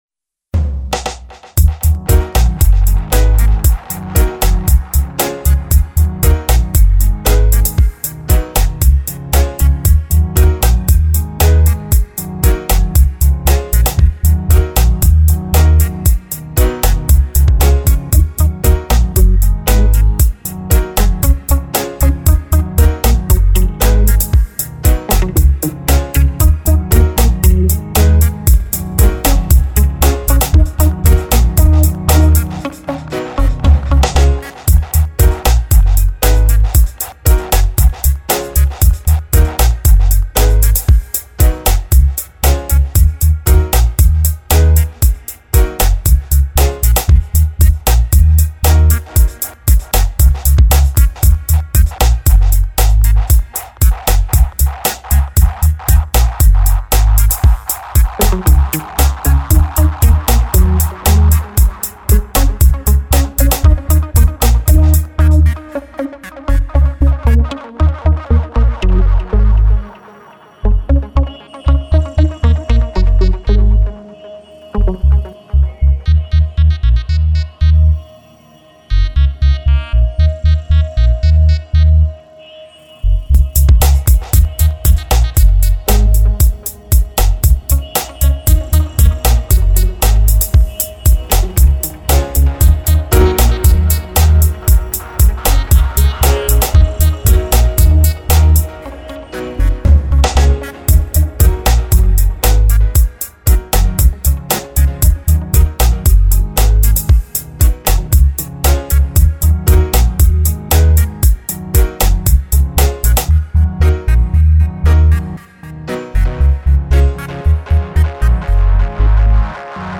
ska reggae dub music